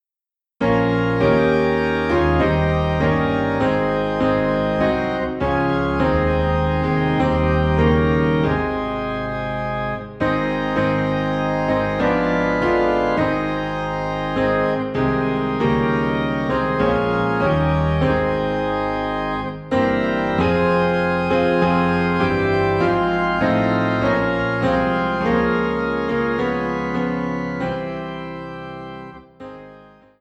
PIANO AND ORGAN DUET SERIES